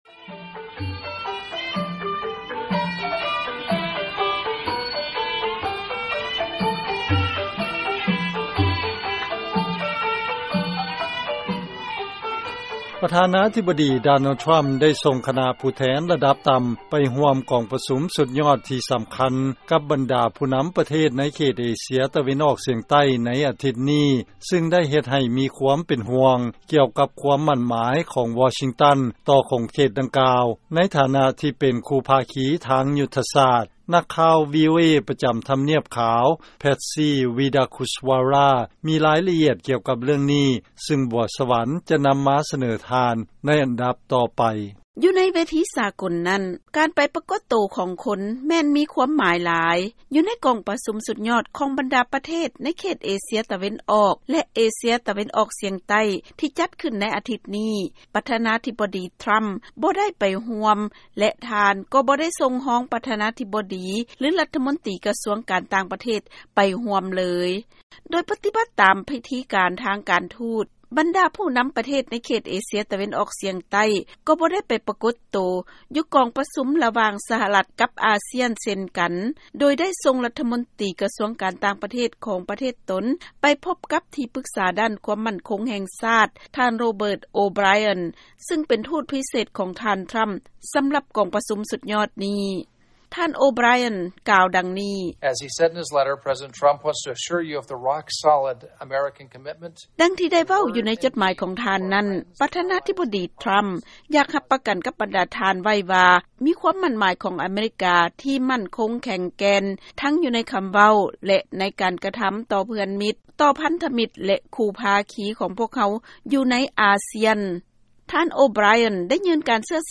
ເຊີນຟັງລາຍງານກ່ຽວກັບ ສຫລ ບໍ່ໄດ້ເຂົ້າຮ່ວມກອງປະຊຸມສຸດຍອດຂອງ ເອເຊຍຕາເວັນອອກ ແລະອາຊຽນ